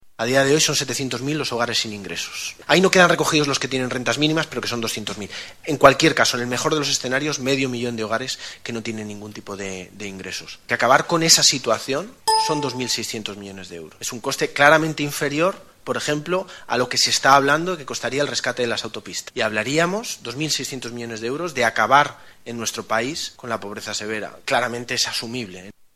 Declaraciones en la Cadena SER 27/03/2014